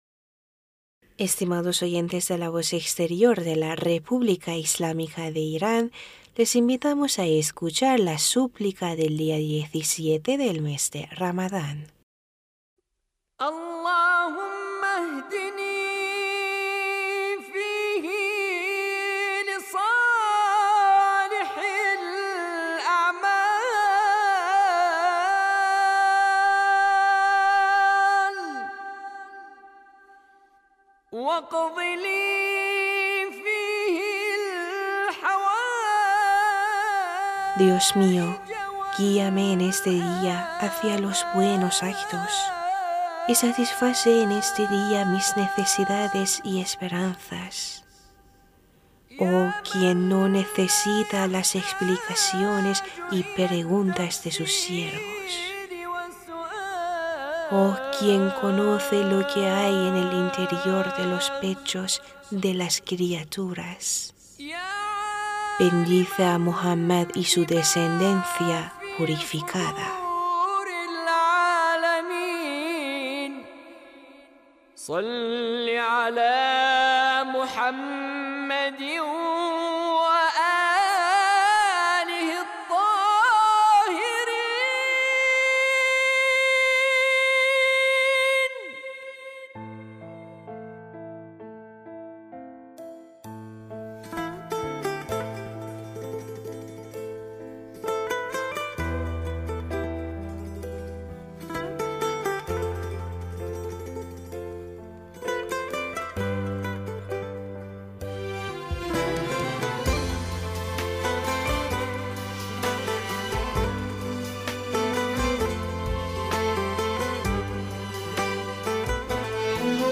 Súplica de 17 día de mes de Ramadán